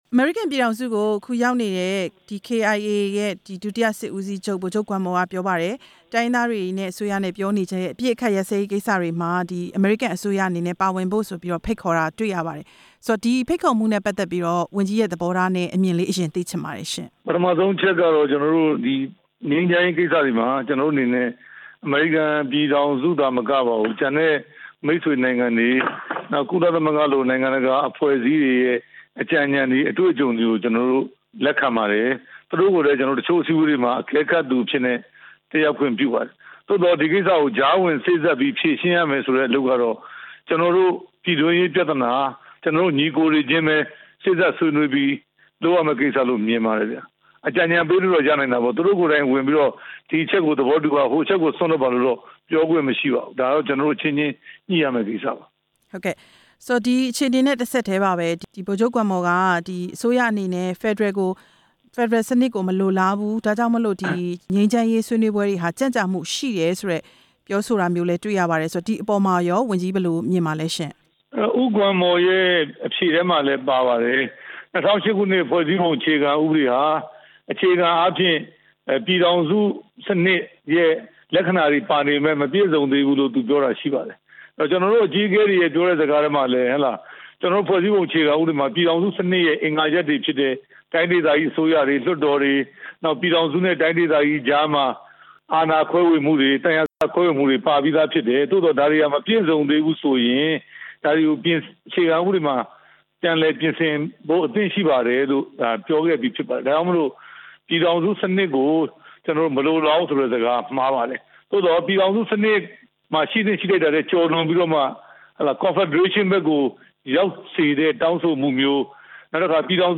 ကချင်ပြည်နယ်က စစ်ပွဲတွေအကြောင်း ဦးရဲထွဋ်ကို မေးမြန်းချက်